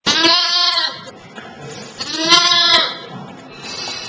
（スマートフォン）   子羊　4秒